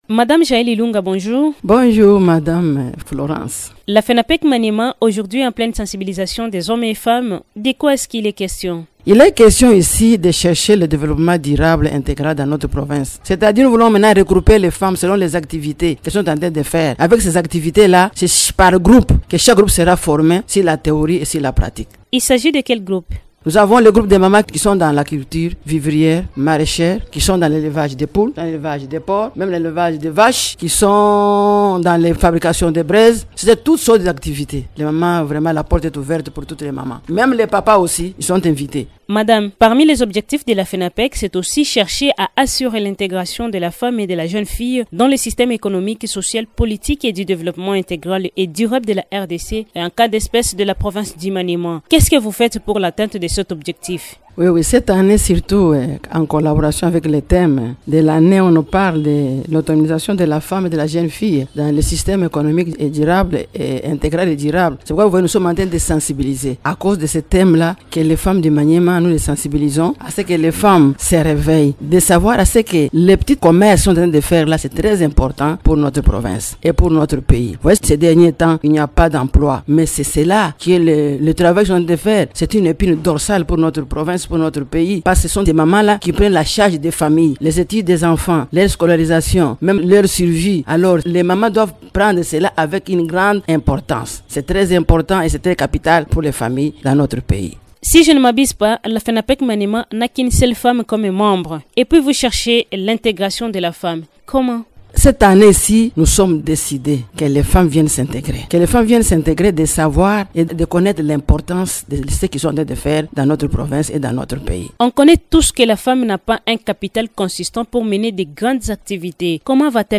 Dans une interview à Radio Okapi